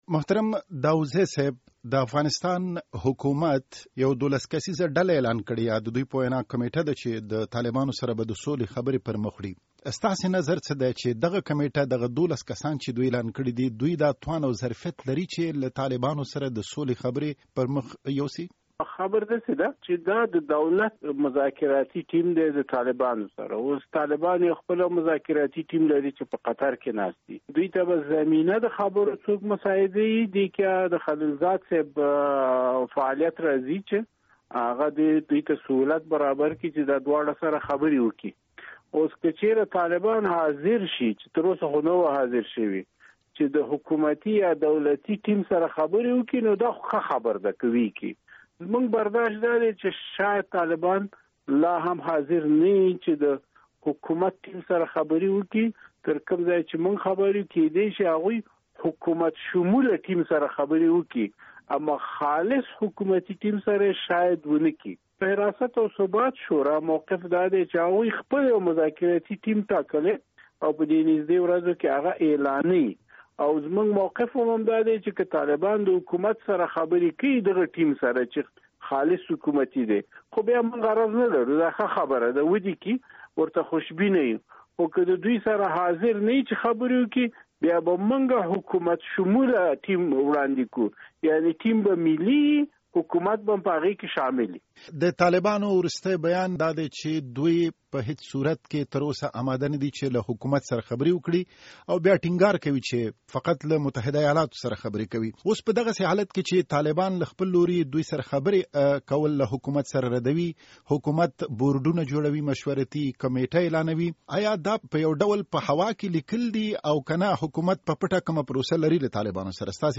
د حراست او ثبات د شورا د بهرنیو اړیکو مسول او د مشرتابه غړي محمد عمر داوودزي ازادي راډیو سره ځانګړې مرکه کړې چې د لاندې لېنک په کېکاږلو سره اورېدلی شئ:
له داوودزي سره مرکه